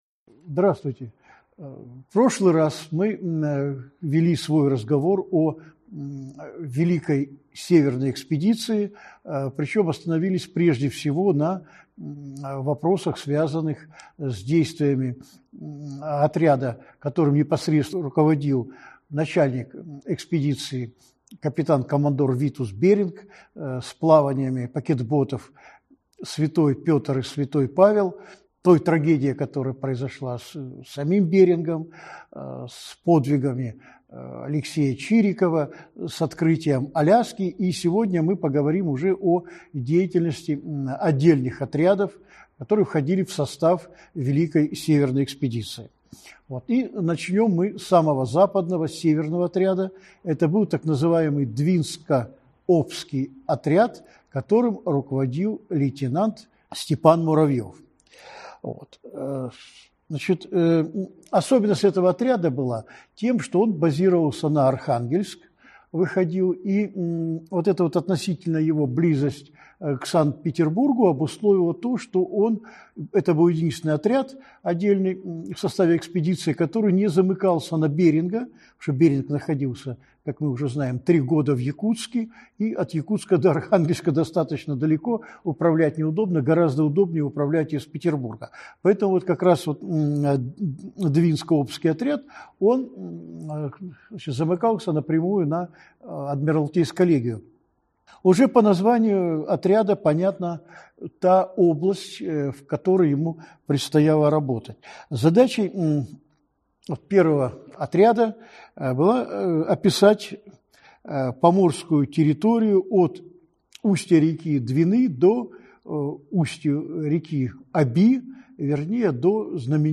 Аудиокнига Великая Северная экспедиция. Подвиг во льдах.